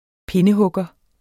Udtale [ ˈpenəhɔgʌ ]